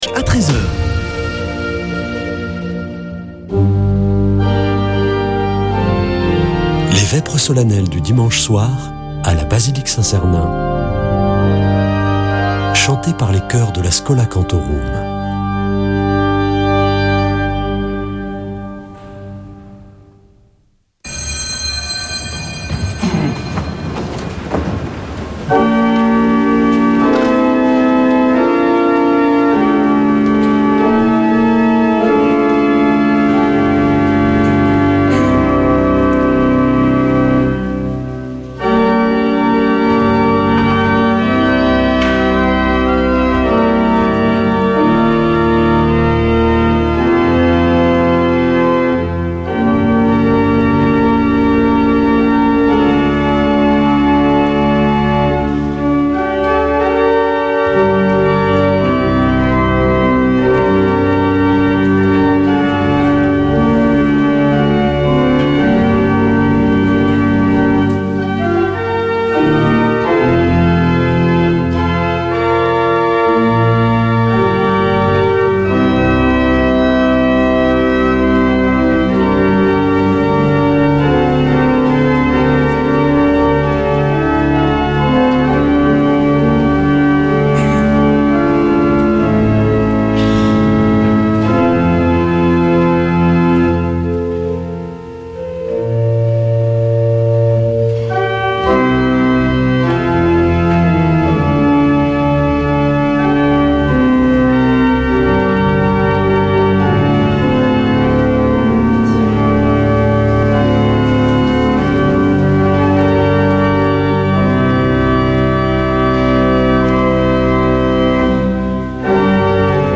Accueil \ Emissions \ Foi \ Prière et Célébration \ Vêpres de Saint Sernin \ Vêpres de Saint Sernin du 02 avr.
Une émission présentée par Schola Saint Sernin Chanteurs